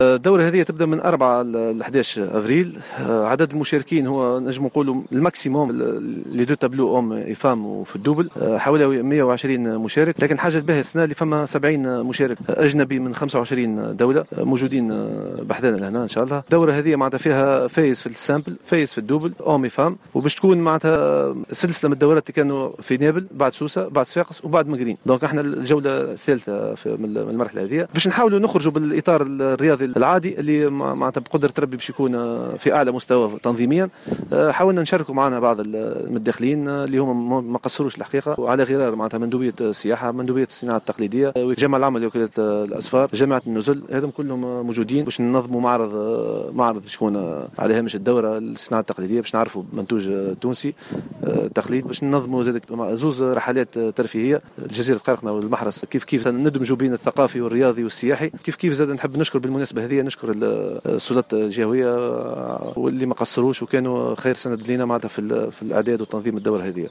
انعقدت اليوم الأحد 04 أفريل 2021، الندوة الصحفية الخاصة بانطلاق الدورة الدولية للأواسط في التنس بنادي التنس بصفاقس.